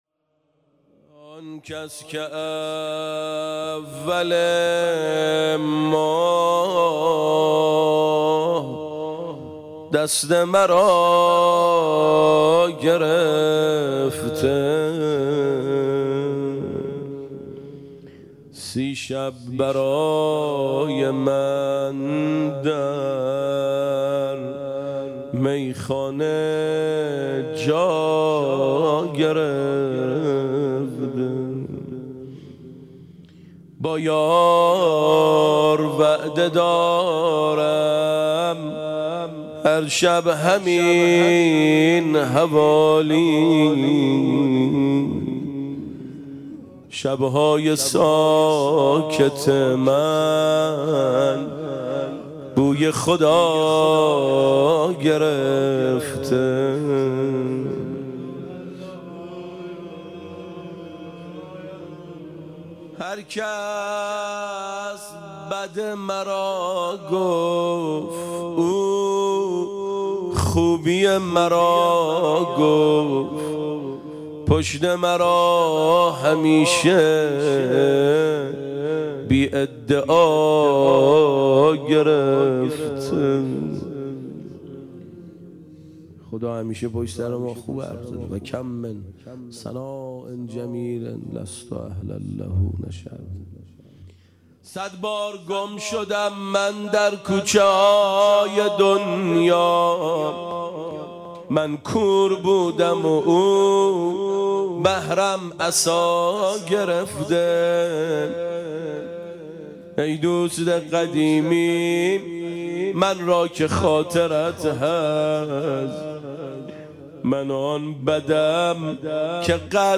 خیمه گاه - روضةالشهداء - شعر مناجاتی
شب دوم ماه رمضان / ۱۴ فروردین ۴۰۱ شعر مناجاتی شعر خوانی ماه رمضان اشتراک برای ارسال نظر وارد شوید و یا ثبت نام کنید .